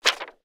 paper_putdown3.wav